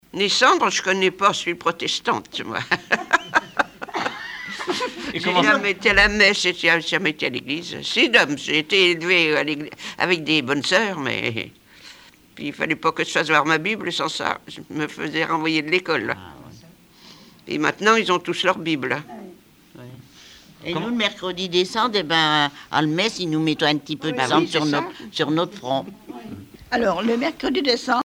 Témoignages liés aux rituels du calendrier
Catégorie Témoignage